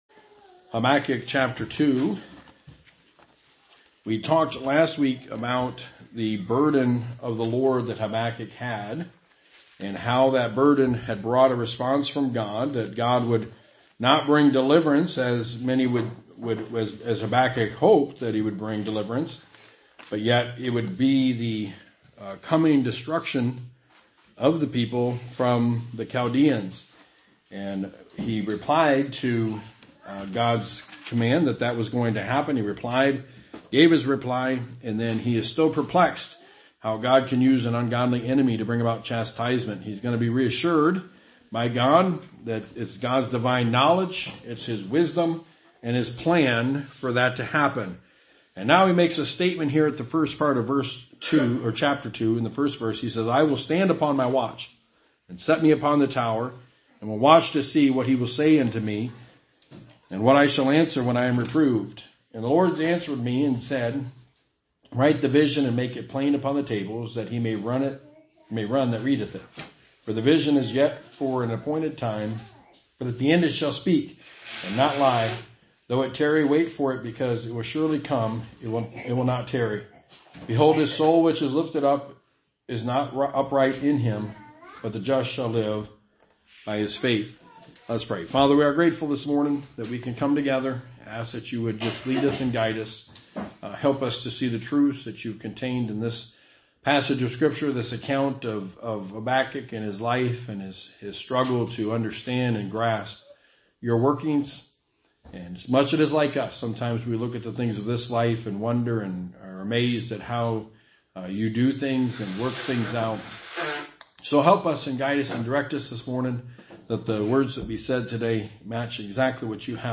The Book of Habakkuk – Sermon #4
Passage: Habakkuk 2:1-4 Service Type: Sunday Morning The Book of Habakkuk